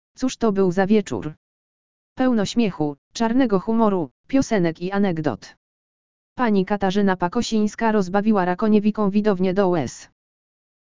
Koncert Wiosenny
Pełno śmiechu, czarnego chumoru, piosenek i anegdot! Pani Katarzyna Pakosińska rozbawiła Rakoniewicką widownie do łez.